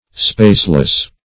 Spaceless \Space"less\, a.